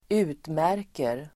Uttal: [²'u:tmär:ker]